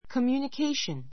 kəmjuːnəkéiʃən コミューニ ケ イション